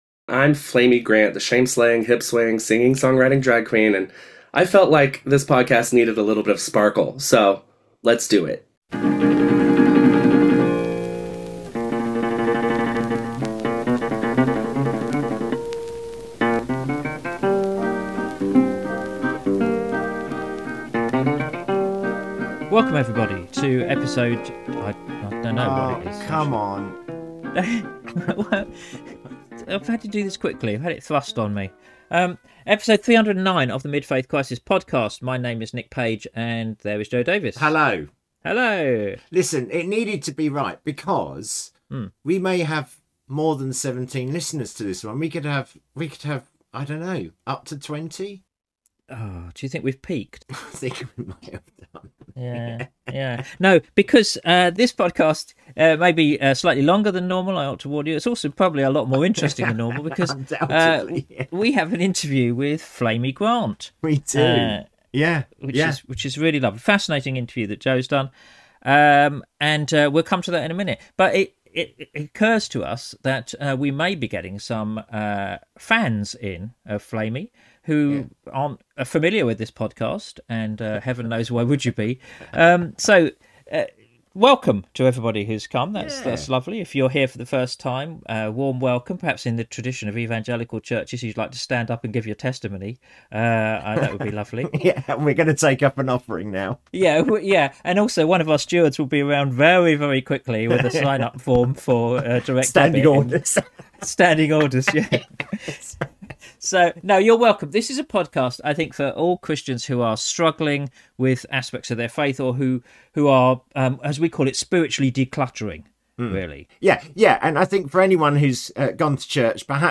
Episode 309: Shame-slayer: An interview with Flamy Grant